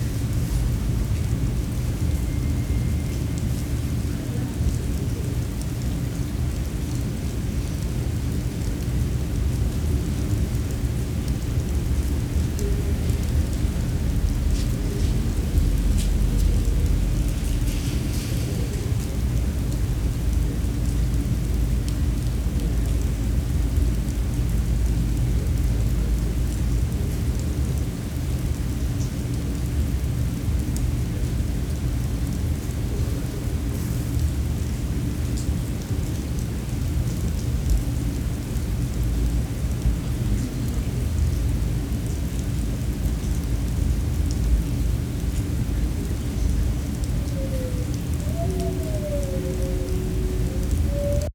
Index of /audio/samples/SFX/IRL Recorded/Rain - Thunder/
Rain 1.wav